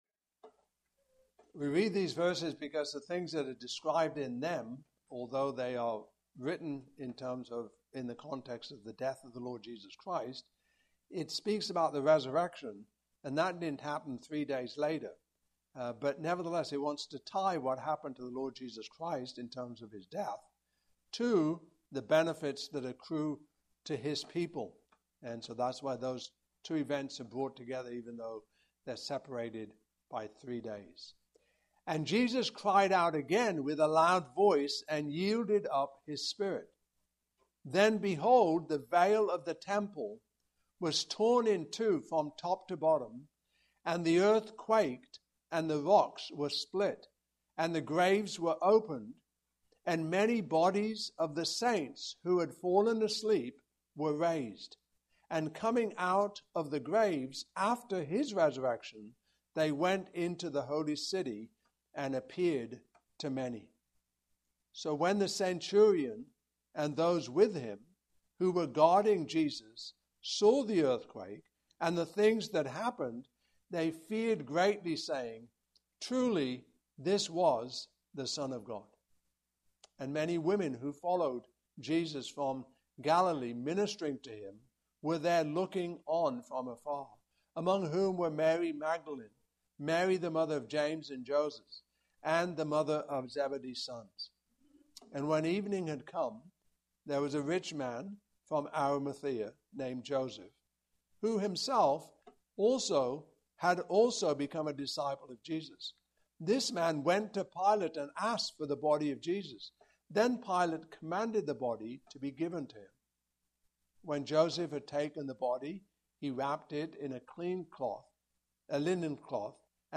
Service Type: Morning Service Topics: The Resurrection